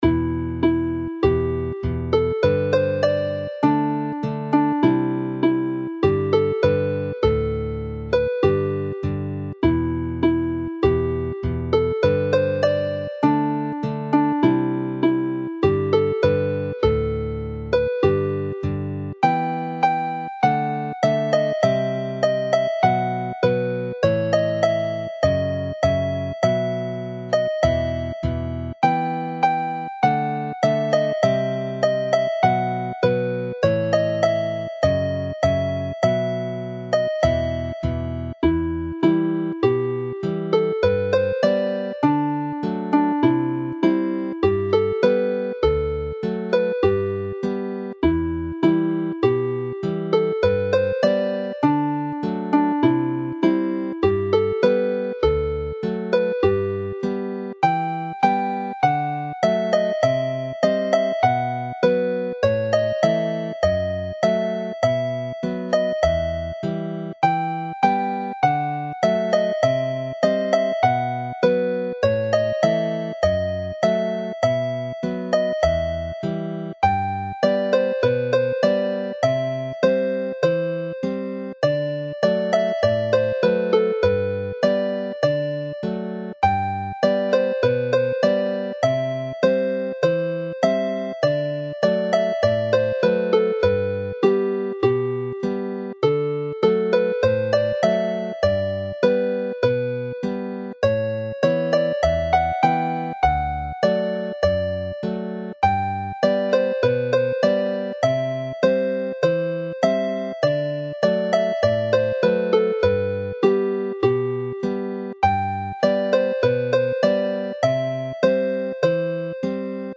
Tune to a traditional dance
Abergenni is a formal dance for four couples based on an old tune which has an Elizabethan feel.